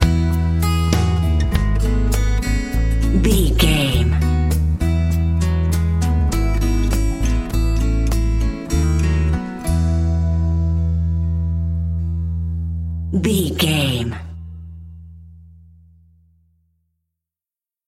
Ionian/Major
drums
acoustic guitar
piano
violin
electric guitar